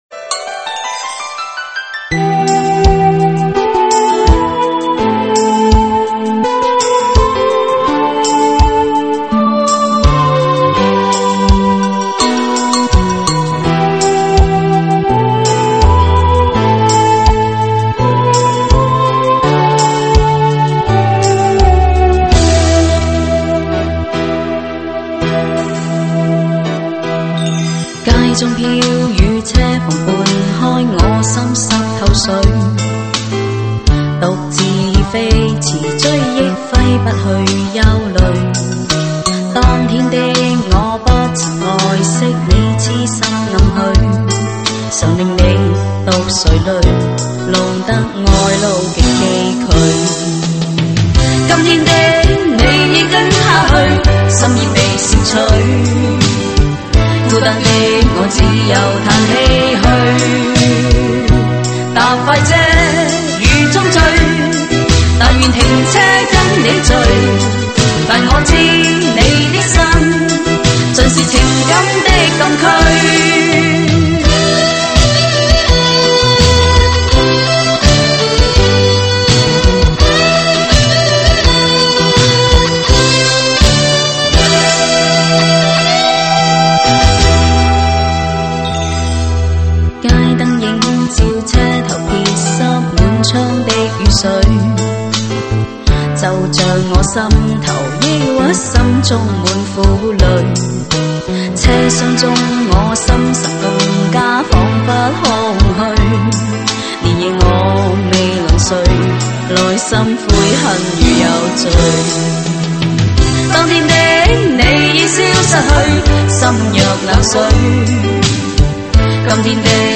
粤语经典